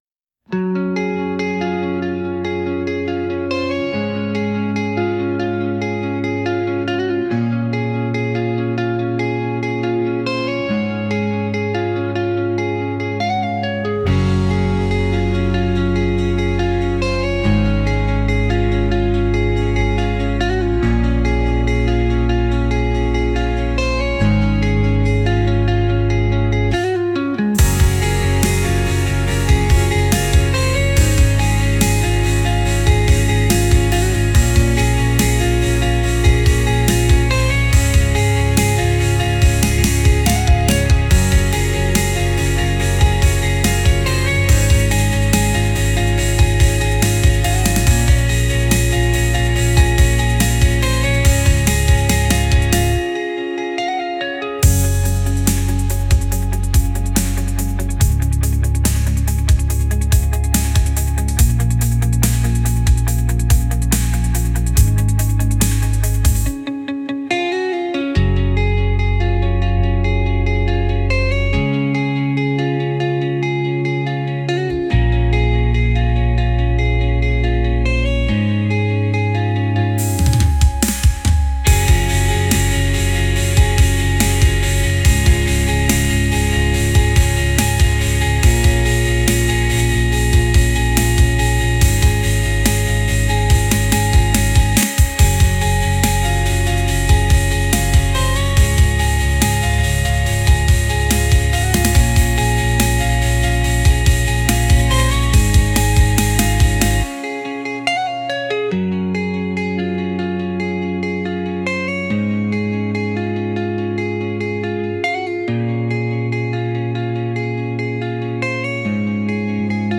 Genre: Melancholic Mood: Electronic Editor's Choice